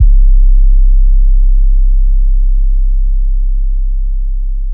Pornography 808.wav